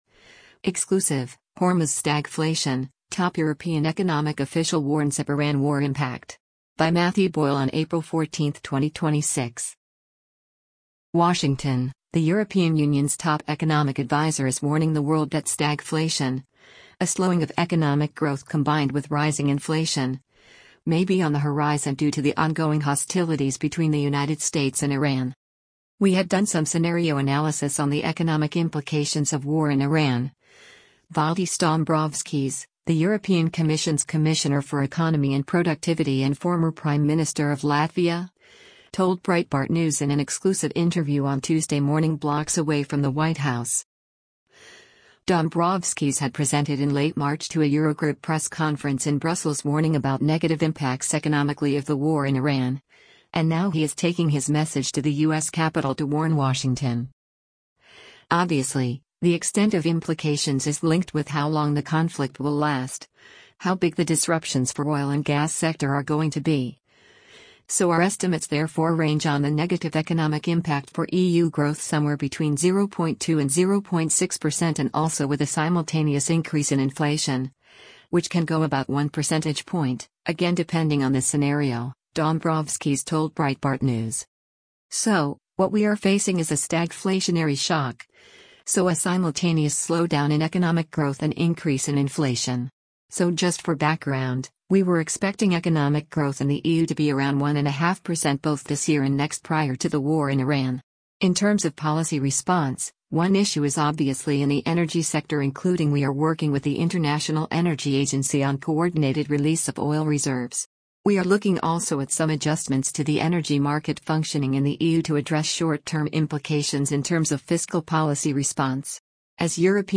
“We had done some scenario analysis on the economic implications of war in Iran,” Valdis Dombrovskis, the European Commission’s Commissioner for Economy and Productivity and former Prime Minister of Latvia, told Breitbart News in an exclusive interview on Tuesday morning blocks away from the White House.